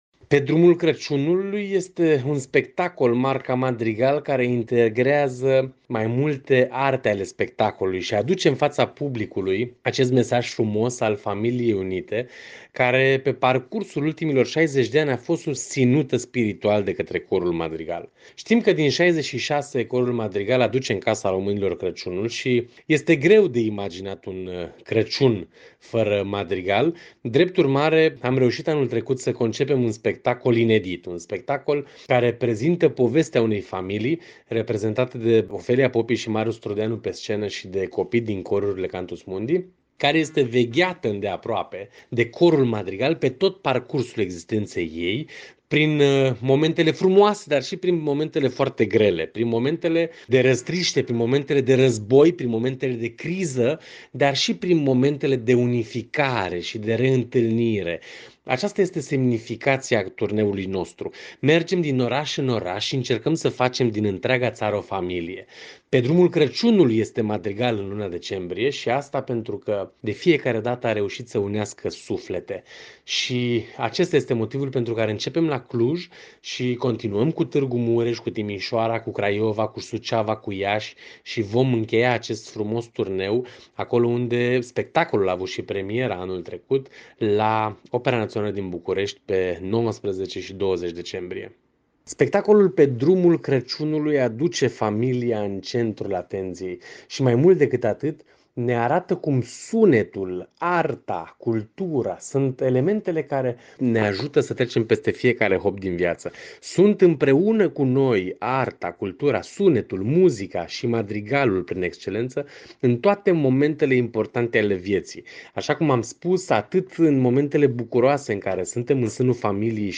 AUDIO: Interviu